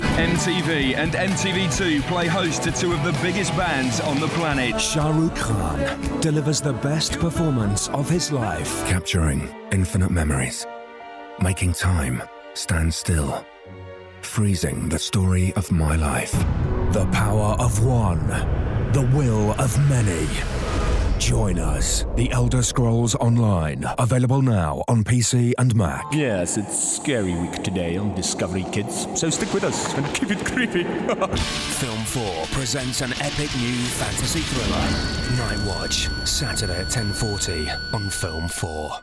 RP ('Received Pronunciation')
Promo, Cool, Energetic, Confident, Bold